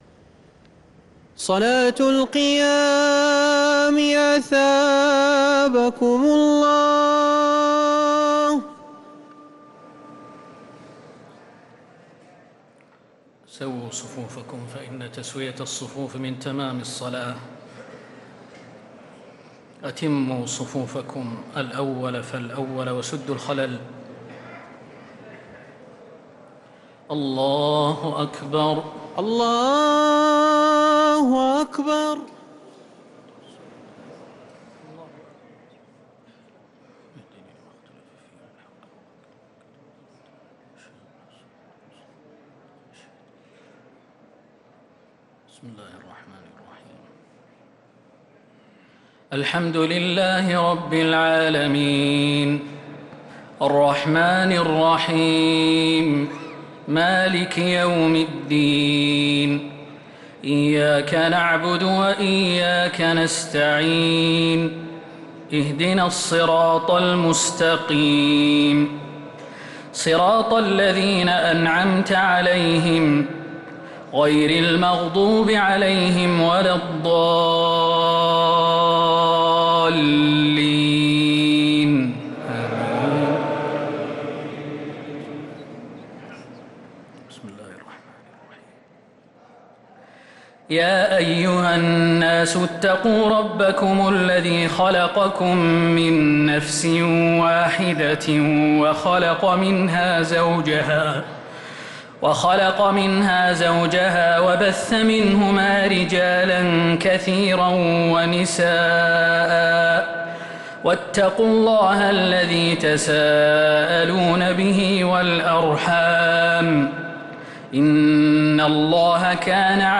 تراويح ليلة 6 رمضان 1446هـ من سورة النساء (1-42) | Taraweeh 6th night Ramadan 1446H Surat An-Nisaa > تراويح الحرم النبوي عام 1446 🕌 > التراويح - تلاوات الحرمين